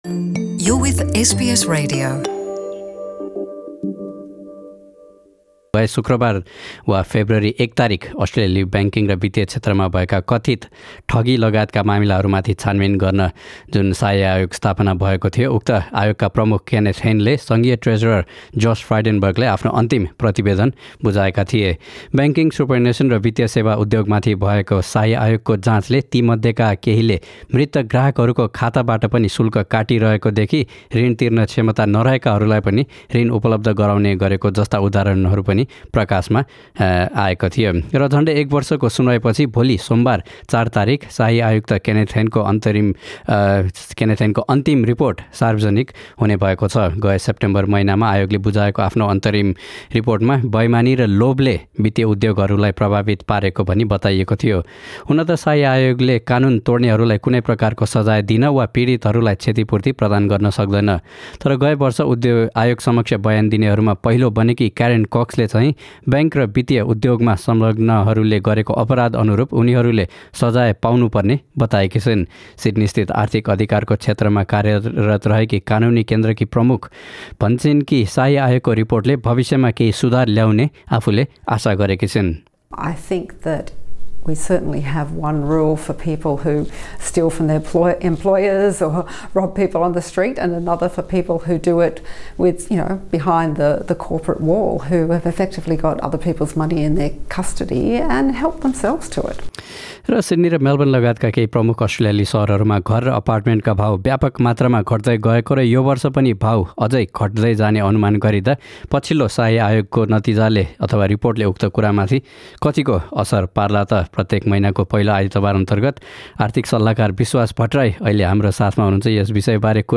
To listen to the full conversation click the play button in media player above.